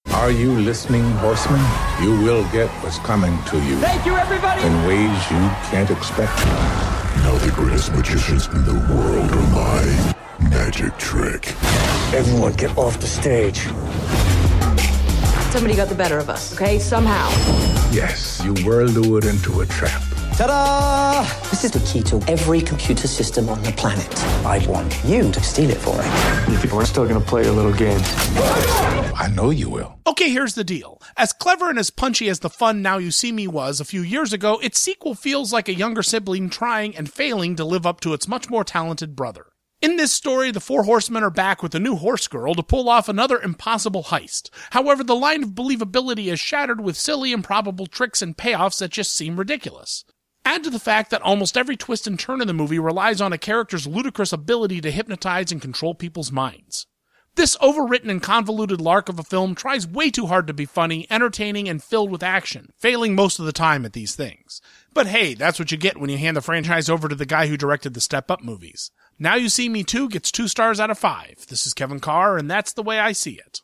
‘Now You See Me 2’ Radio Review